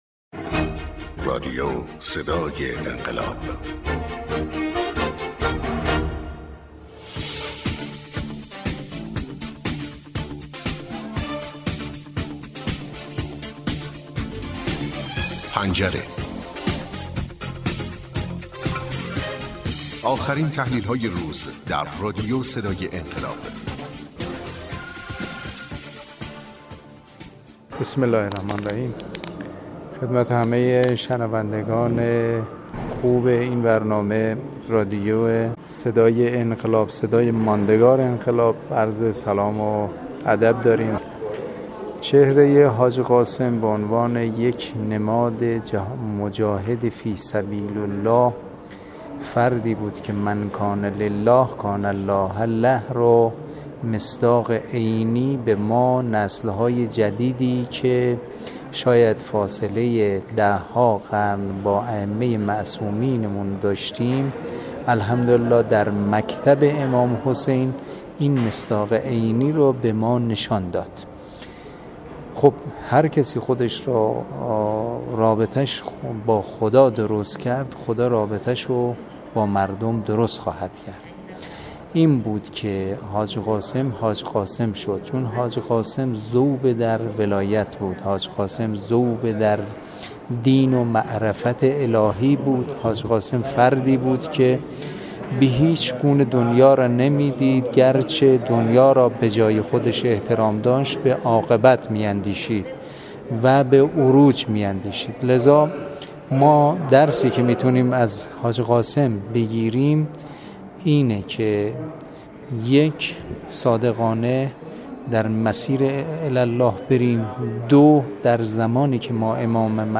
حبیب قاسمی نماینده مردم شهرضا ، شخصیت معنوی حاج قاسم چطور شکل گرفت؟